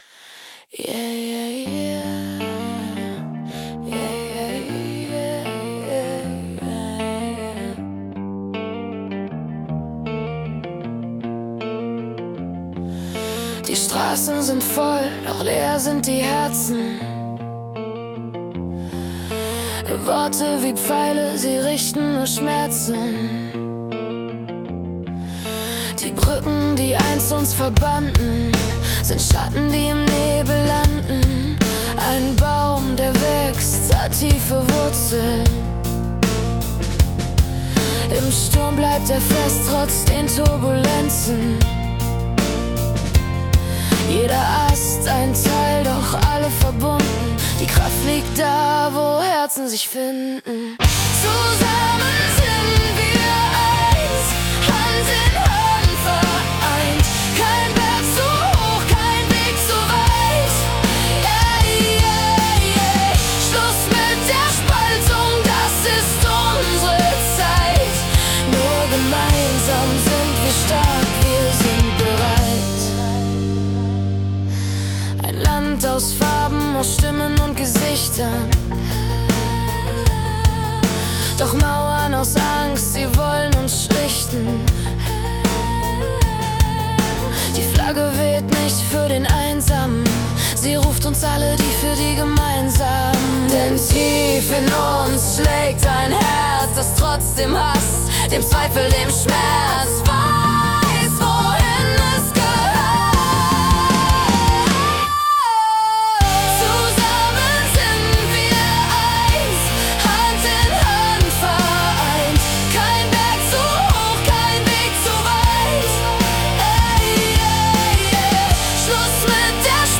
Hier eine Auswahl an Musik, die durch KI erzeugt wurde.